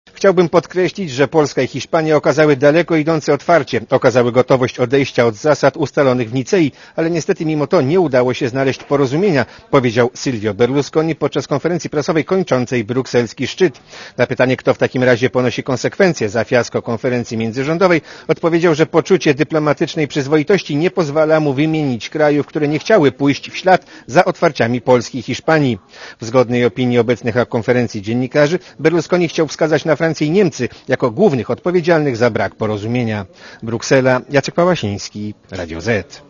Posłuchaj relacji korespondenta Radia Zet (141 KB)